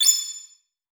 eff_unsheathe.mp3